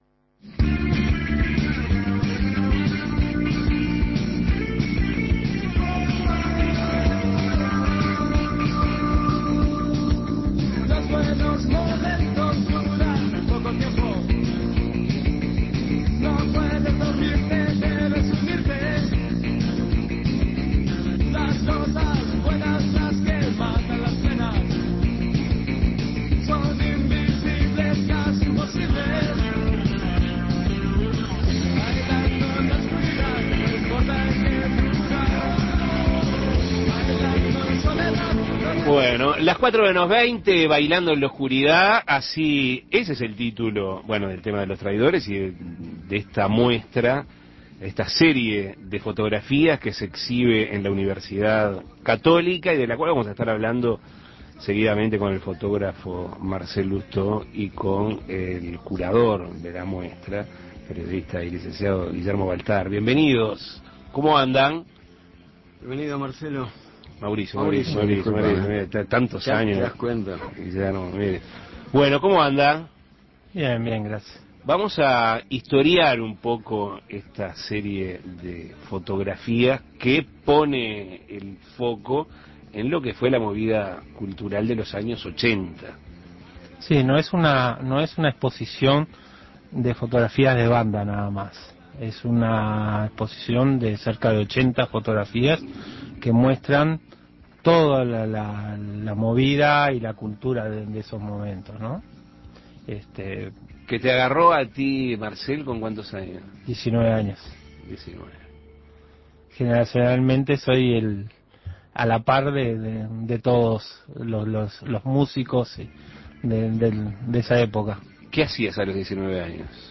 Entrevistas "Bailando en la oscuridad" Imprimir A- A A+ "Bailando en la Oscuridad" pretende ser un retrato del rock de la década de los 80´.